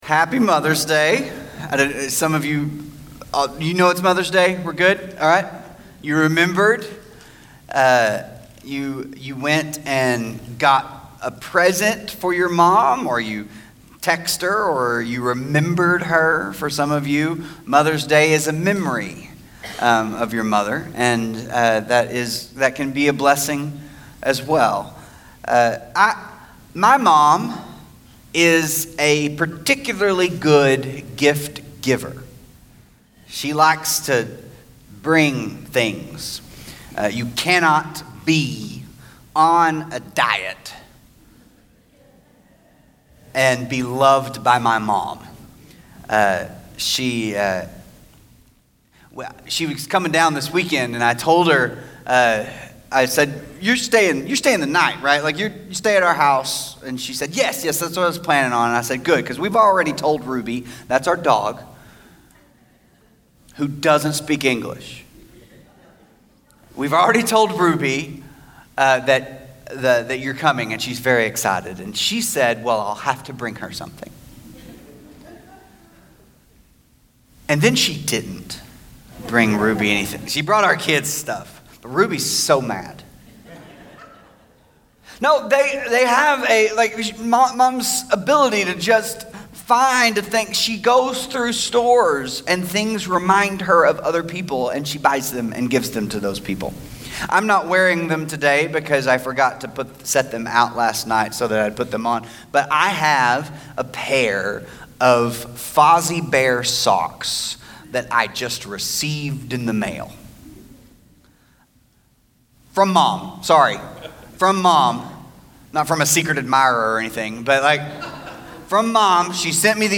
God of Good Gifts - Mother's Day Sermon